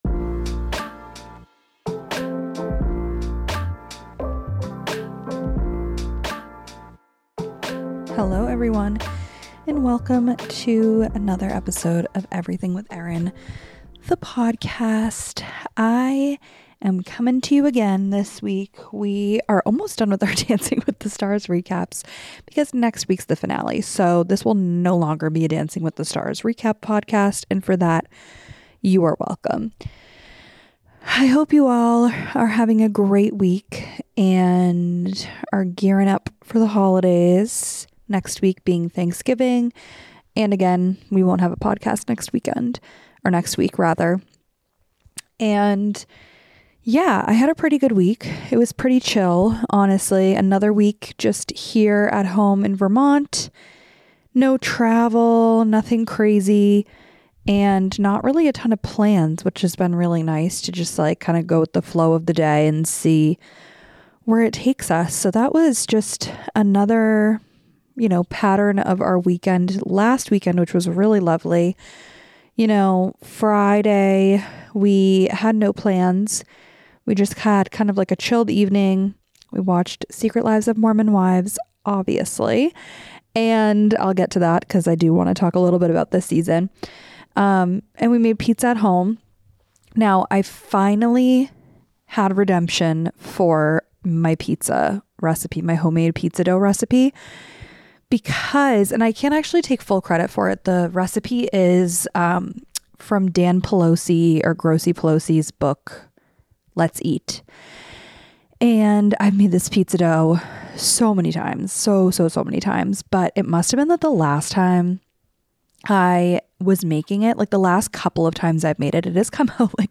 Coming to you from my couch where we settle in and recap the past week!